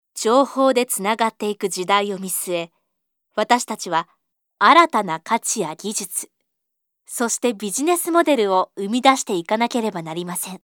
日语T6-女1 商业 娓娓道来|积极向上|时尚活力|神秘性感|亲切甜美|素人